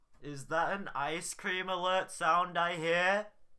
icecreamalertsound.mp3